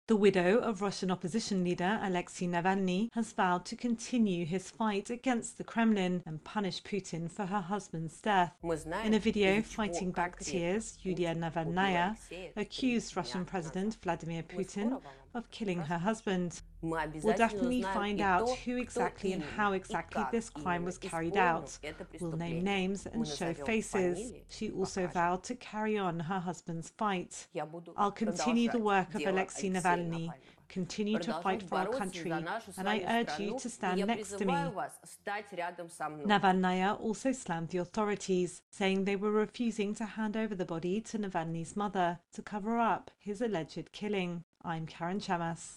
reports on the wife of former Russian opposition leader Alexei Navalny.